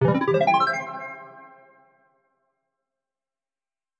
mixkit-casino-bling-achievement-2067.mp3